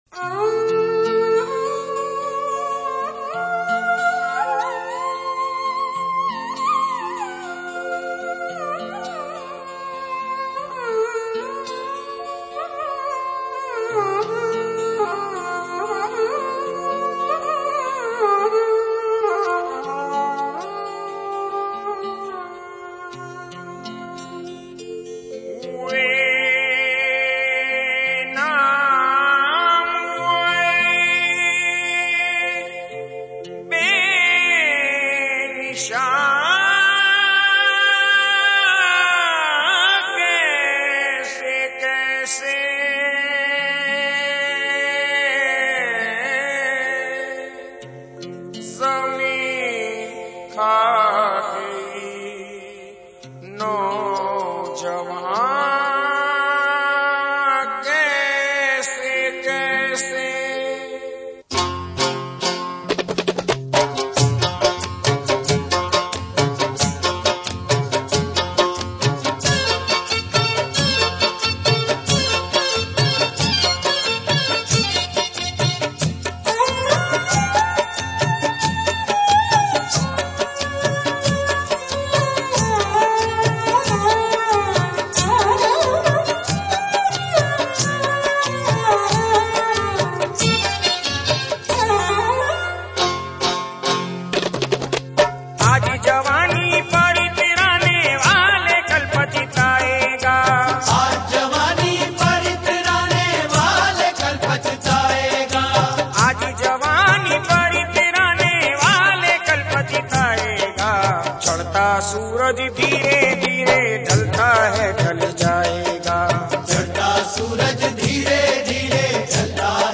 qawali